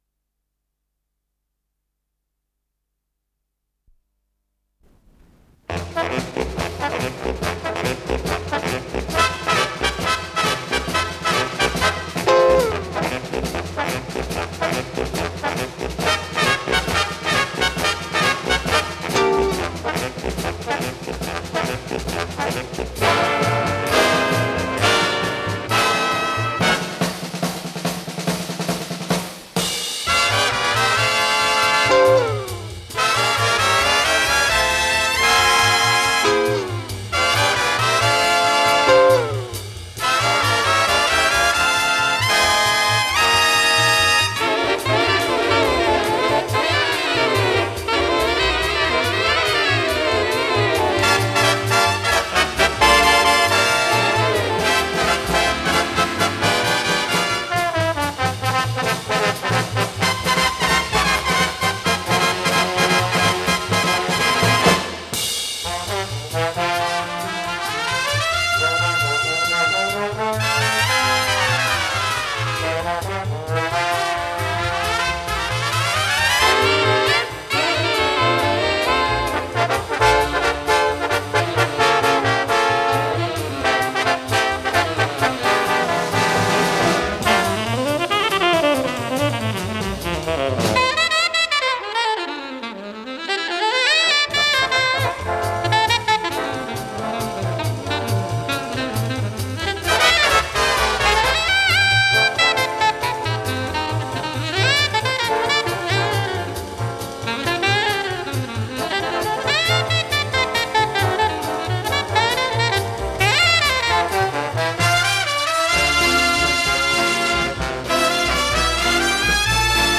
с профессиональной магнитной ленты
альт-саксофон